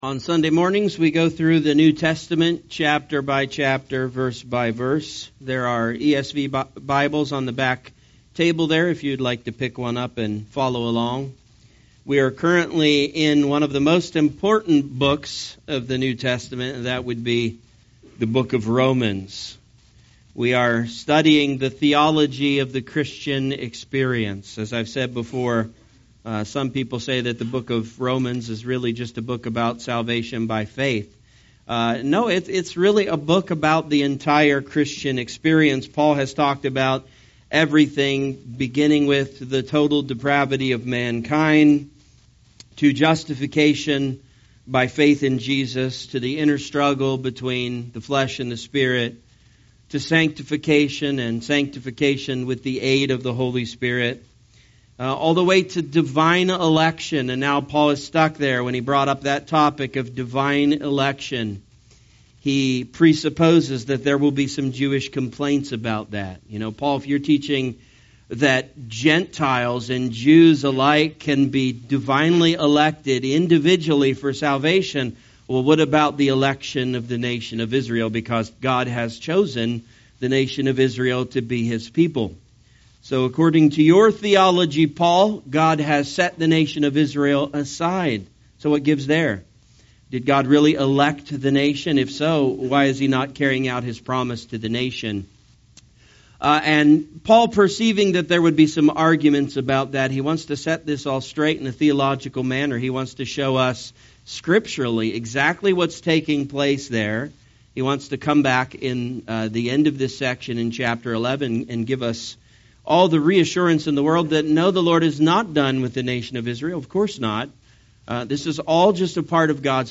A message from the topics "The Book of Romans."